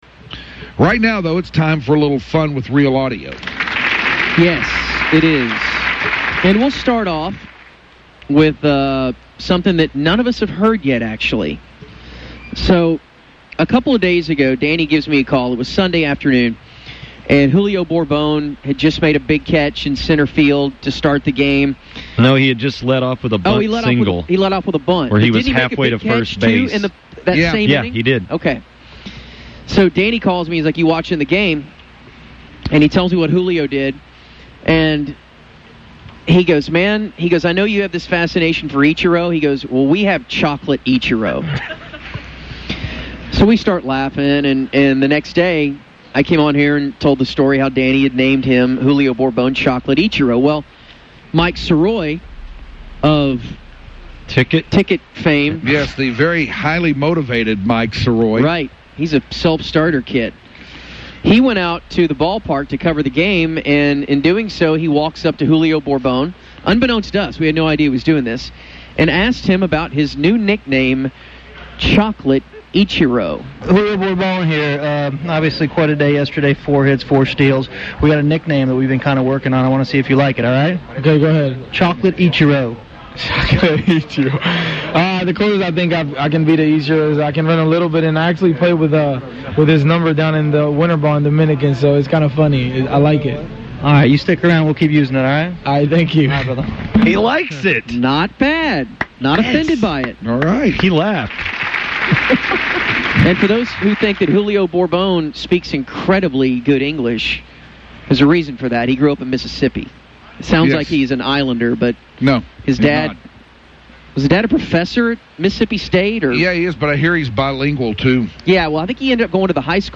The audio kind of skips in the middle, but there was only a few seconds missed.